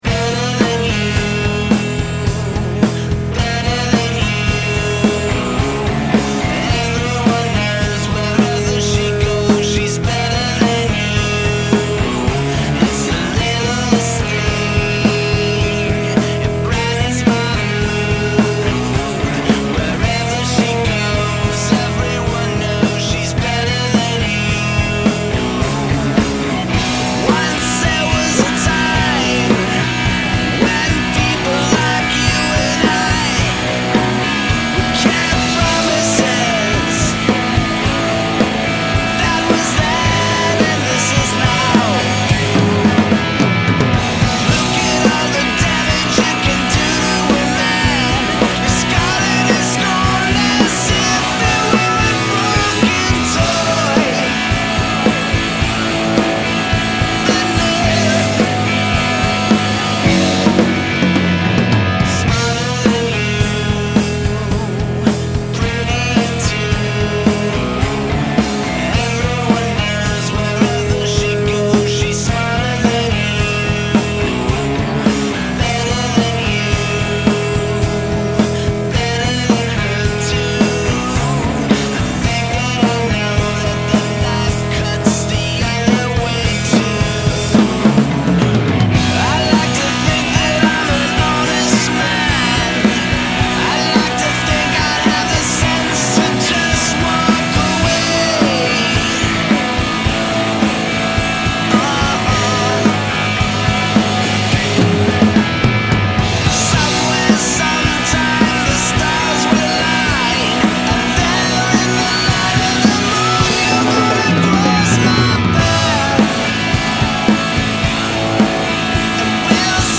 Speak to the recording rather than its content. The entire master will have a notch between 400-500hz to ameliorate the boxiness.